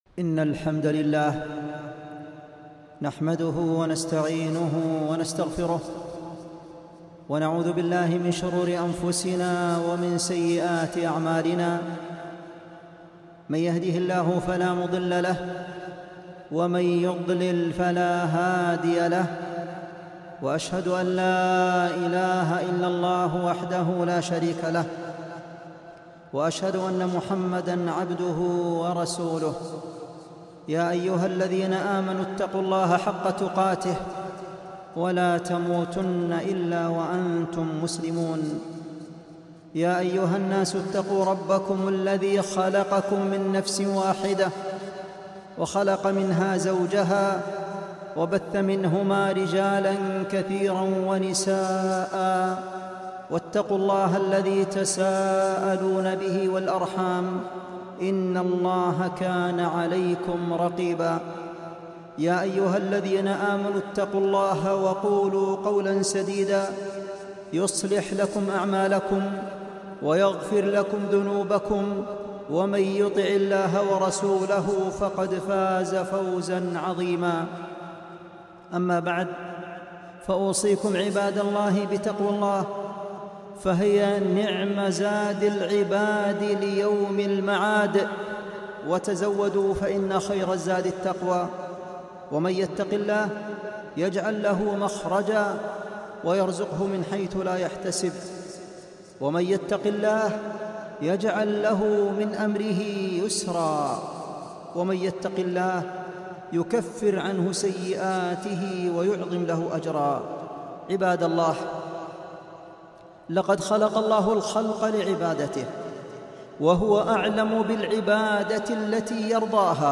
خطبة - تعظيم قدر العلماء